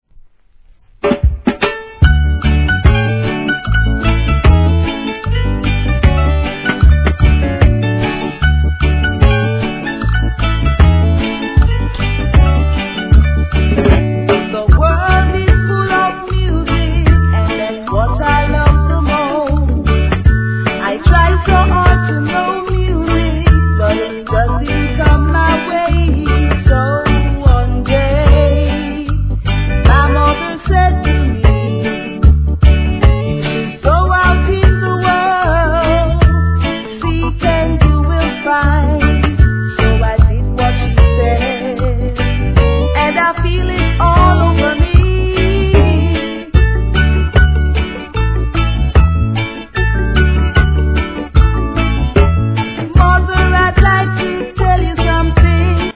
REGGAE
暖かい素晴らしい内容!